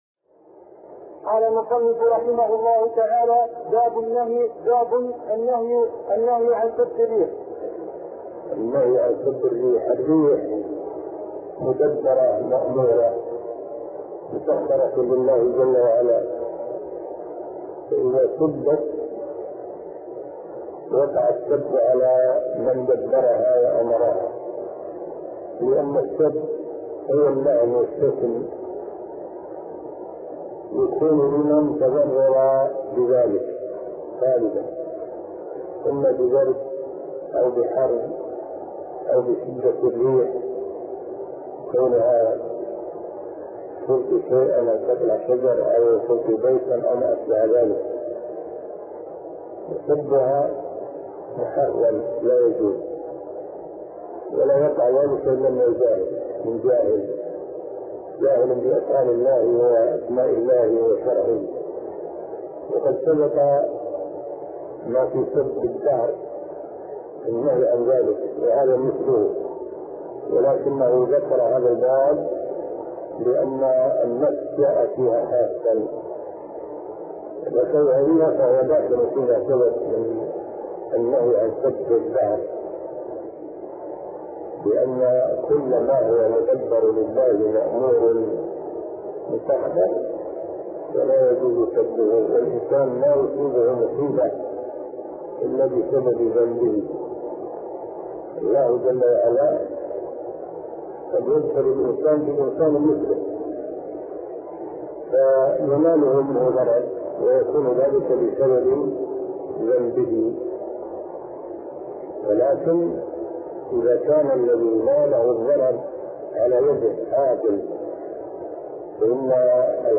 عنوان المادة الدرس ( 123) شرح فتح المجيد شرح كتاب التوحيد تاريخ التحميل الجمعة 16 ديسمبر 2022 مـ حجم المادة 7.34 ميجا بايت عدد الزيارات 236 زيارة عدد مرات الحفظ 135 مرة إستماع المادة حفظ المادة اضف تعليقك أرسل لصديق